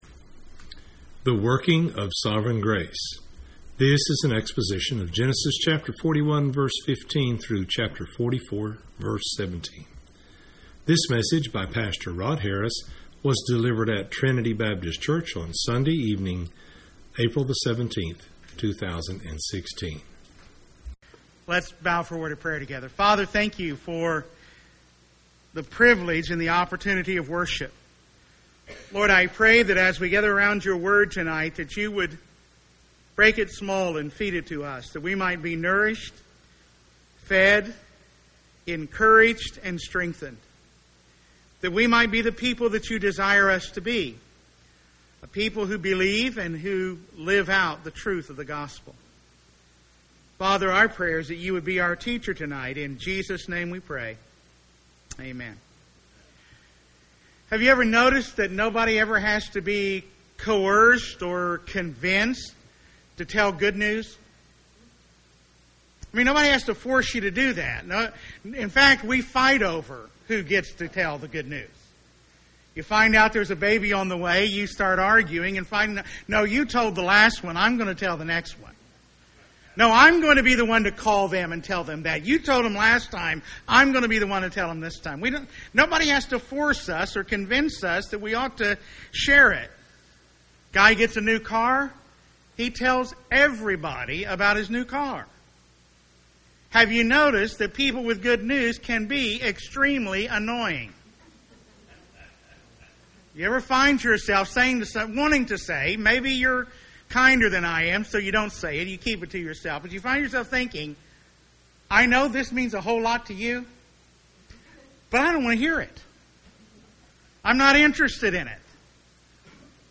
was dleivered at Trinity Baptist Church on Sunday evening